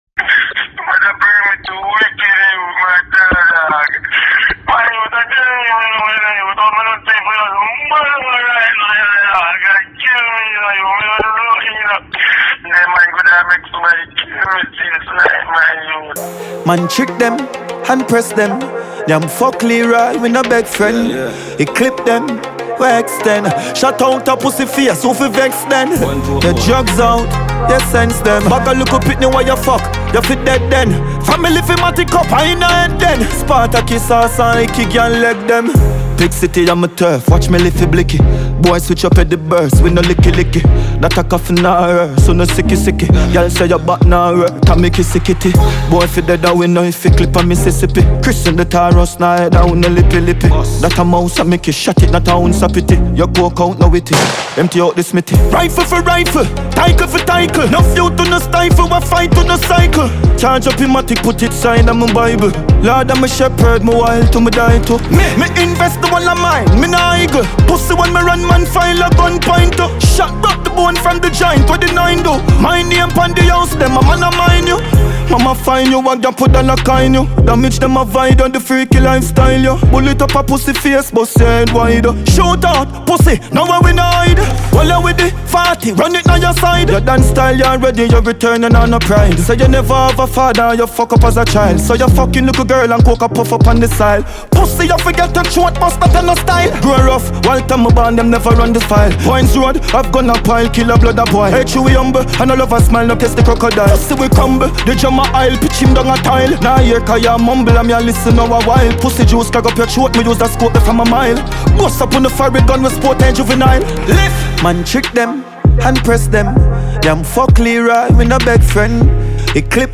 Dancehall
a raw and lyrically venomous diss track